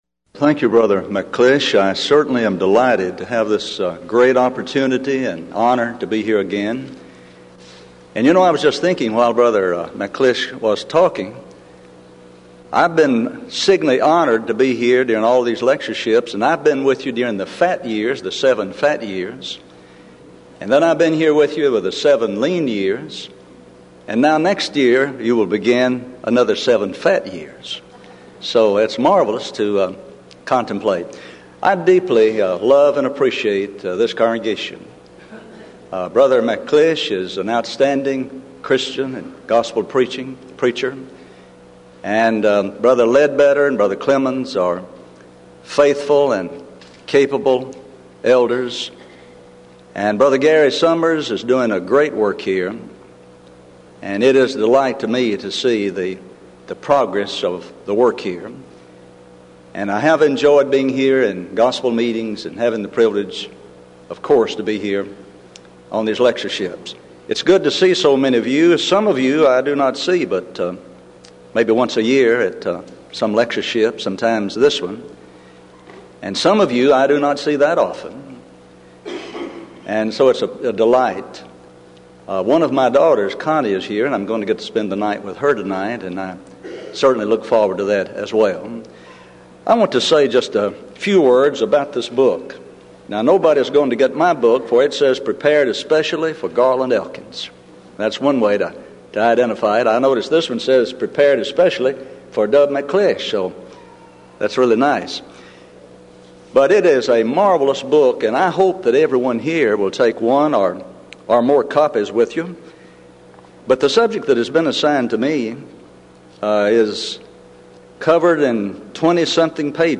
Event: 1995 Denton Lectures
lecture